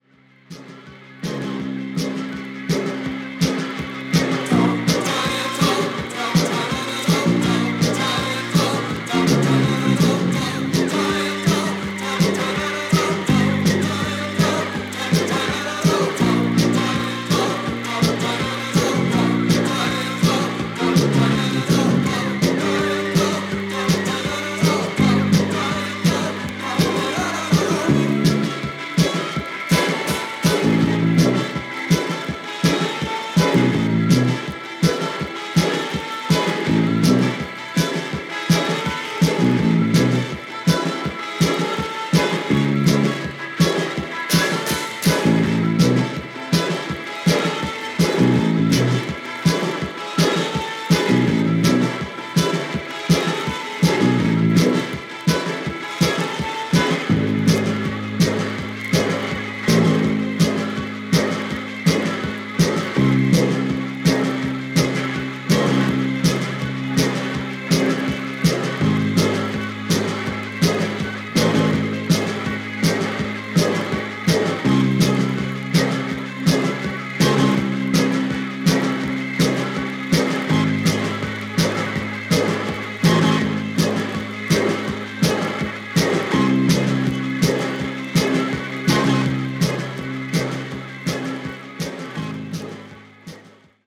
Post-Punk-New Wave